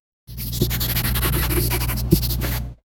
Ambient-sounds Sound Effects - Free AI Generator & Downloads